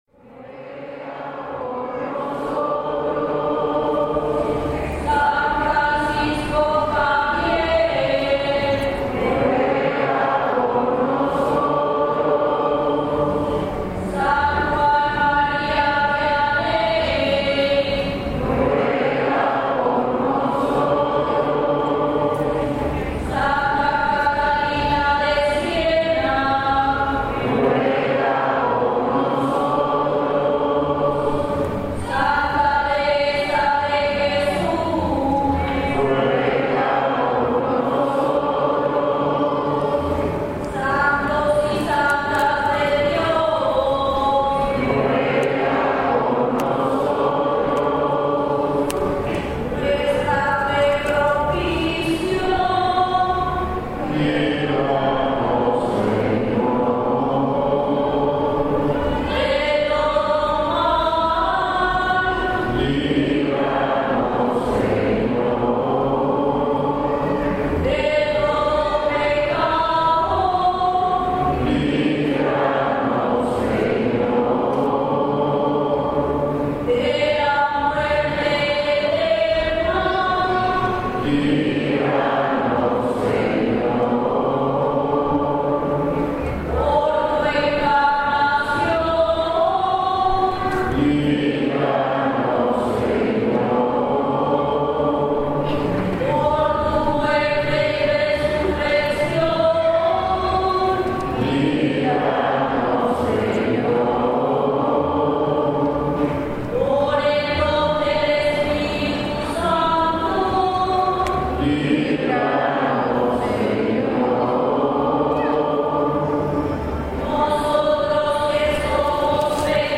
Plegarias
Es Sábado de Gloria en Semana Santa, caminamos por las calles de San Cristóbal de Las Casas, Chiapas, Mexico.
La ciudad cual está llena de turistas nacionales y extranjeros que aprovechan los días de asueto para conocer o visitar esa cosmopolita ciudad. Decidimos entrar al Templo de Santo Domingo, construcción que data del siglo XVII, ubicado en la plaza central, nos sumergimos en otra dimensión que contrasta con el ambiente del exterior del recinto.